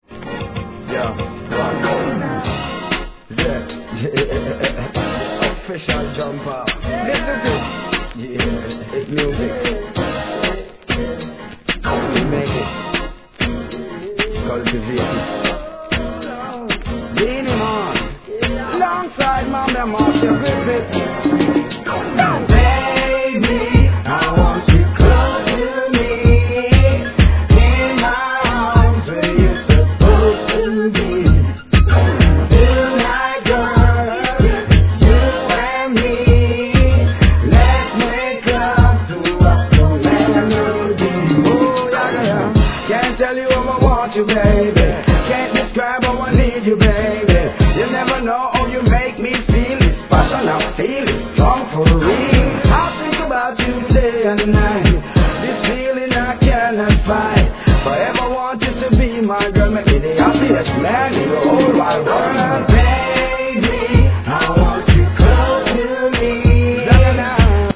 REGGAE
2004年のR&B調洒落オツJUGGLIN'人気チュ〜ン!!